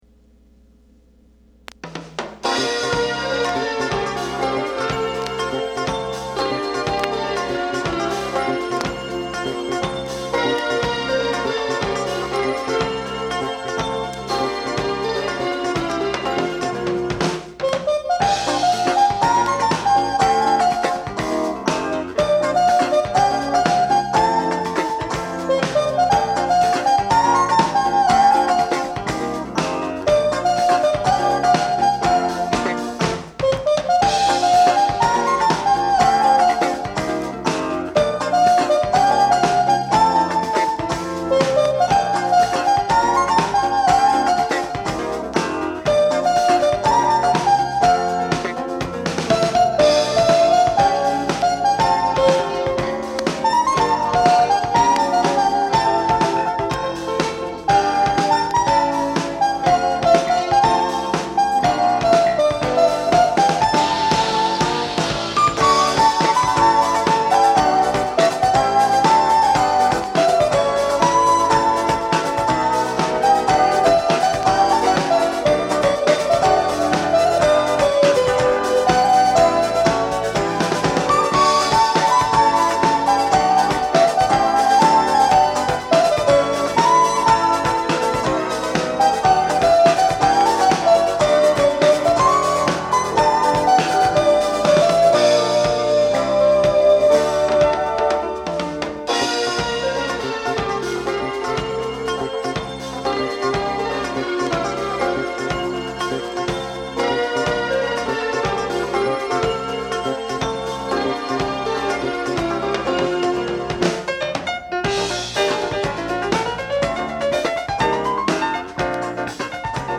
Genre: Jazz Fusion